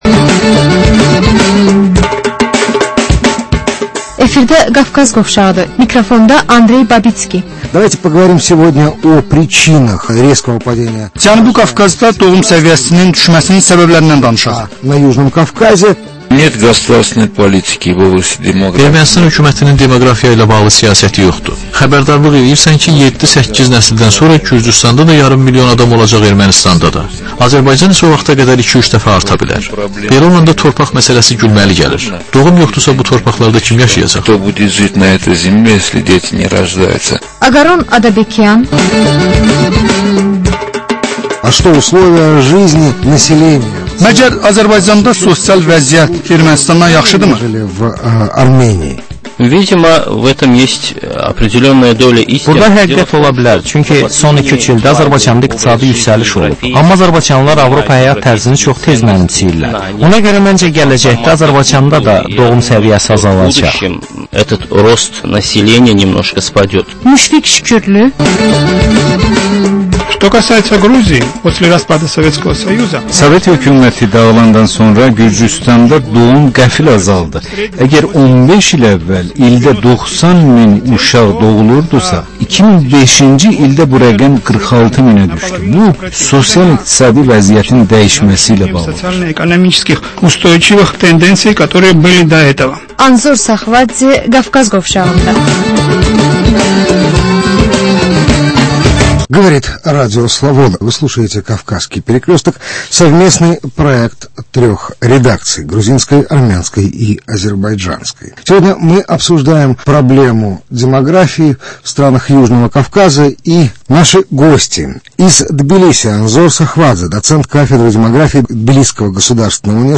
Azərbaycan, Gürcüstan və Ermənistandan reportajlar